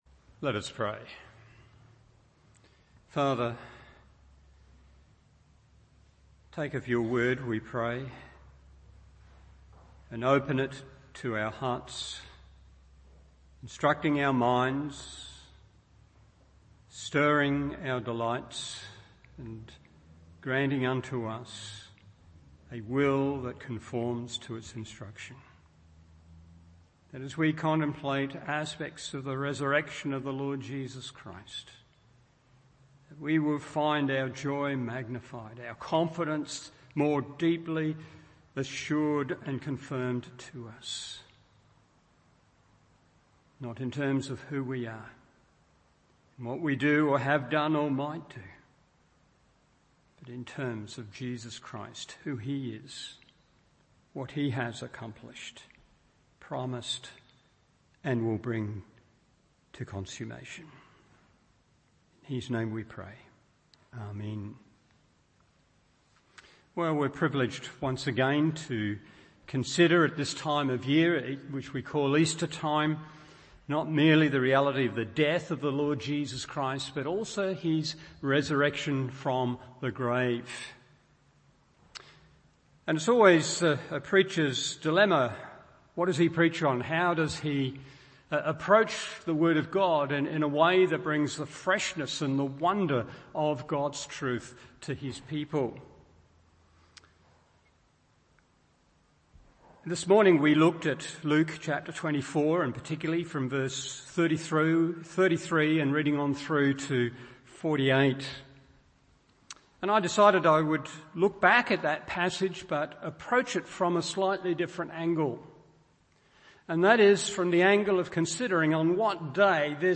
Evening Service Luke 24:33-43 1.